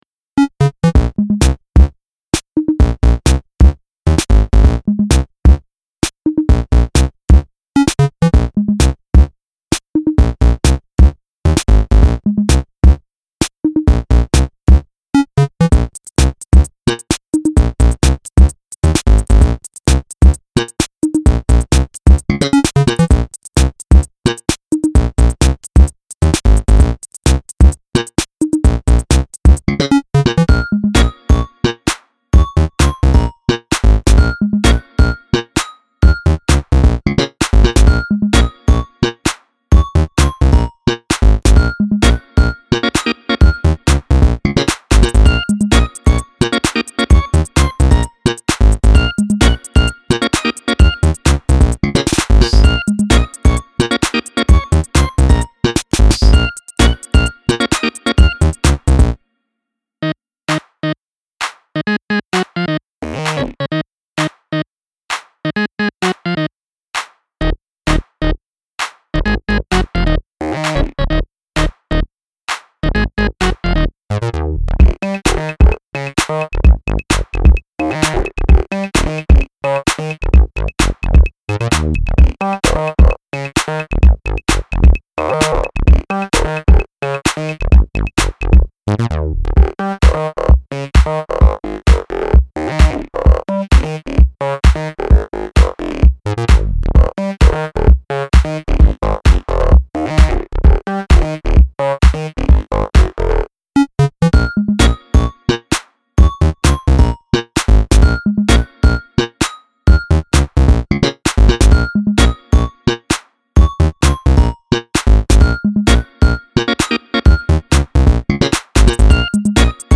Quirky 8-bit groovy 80s funk.